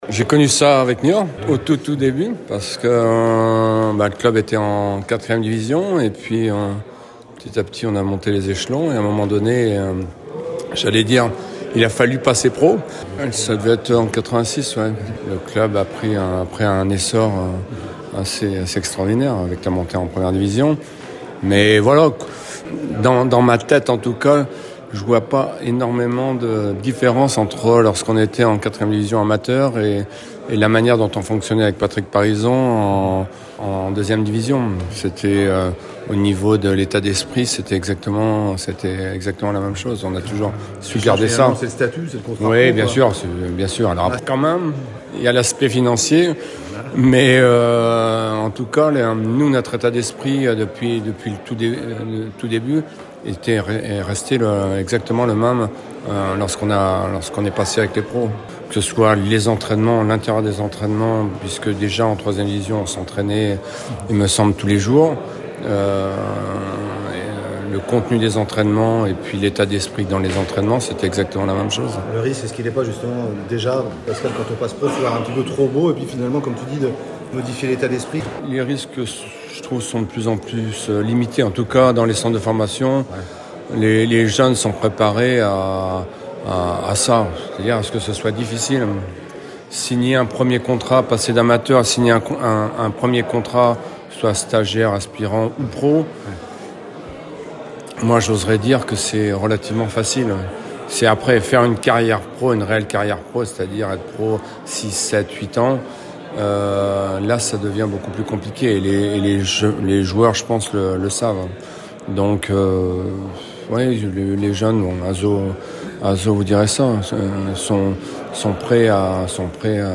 Entretien avec Pascal Gastien